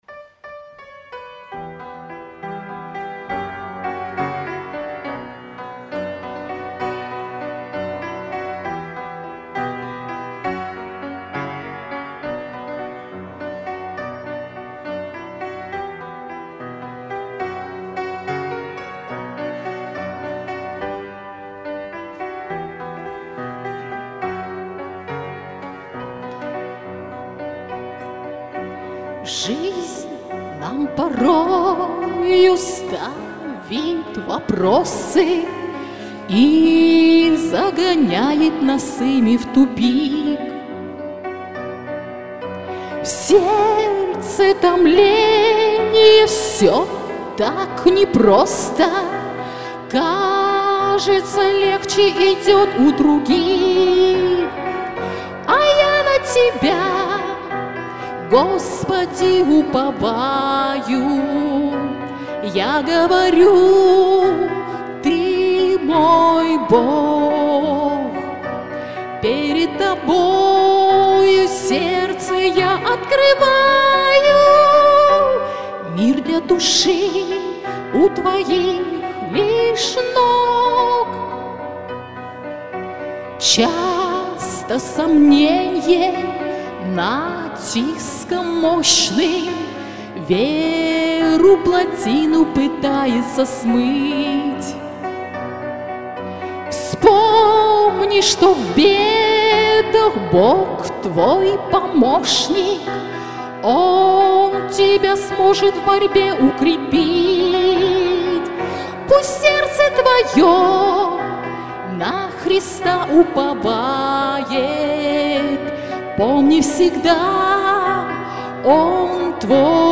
Богослужение 15.09.2024
Пение